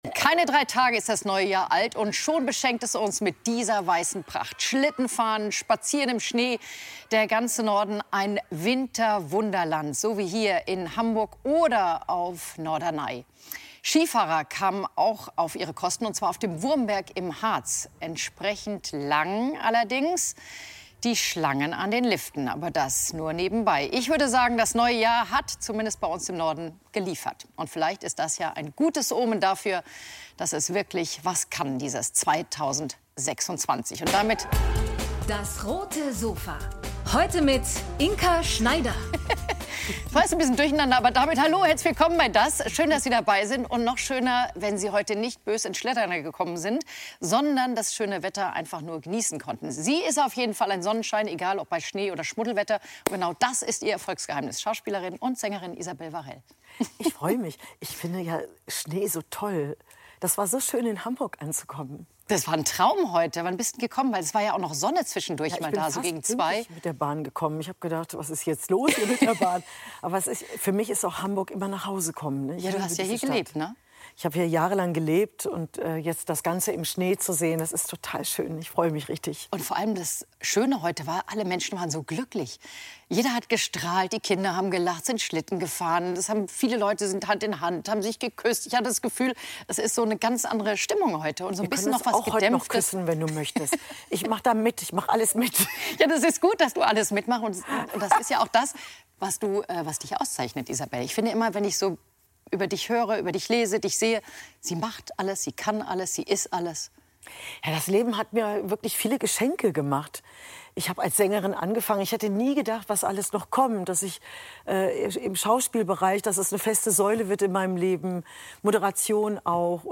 Sängerin und Schauspielerin Isabel Varell auf dem Roten Sofa ~ DAS! - täglich ein Interview Podcast